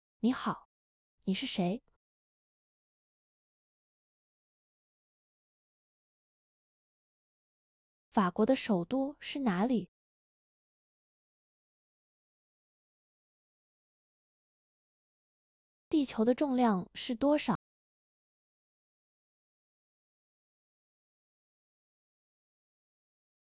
three_utterances_simple.wav